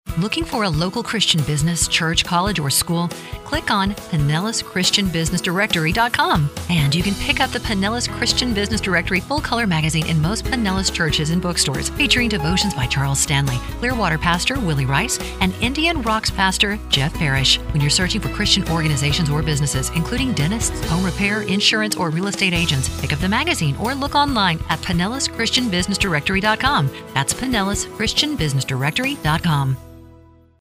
Listen to Our Radio Advertisement Don't miss being found in our next issue Find out why you should List Your Business
Pinellas_Radio_Commercial.mp3